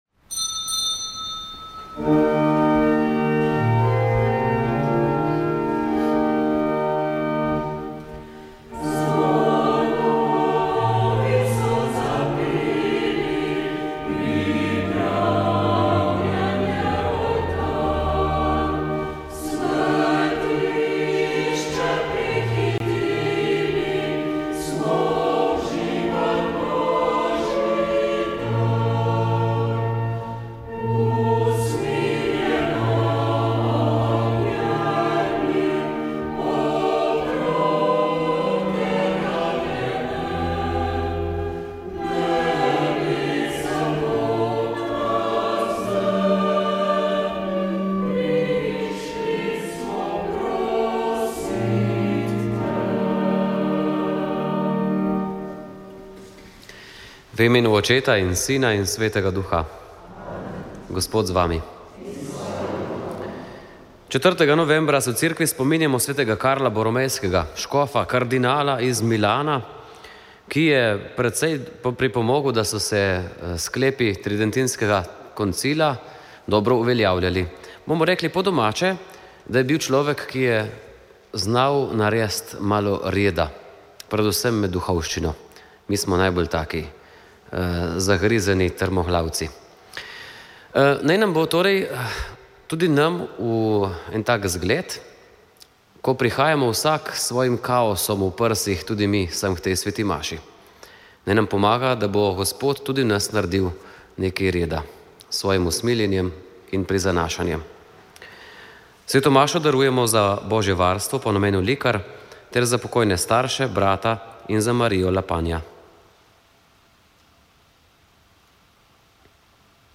Sveta maša
Prenos svete maše iz bazilike Marije Pomagaj na Brezjah dne 16.5.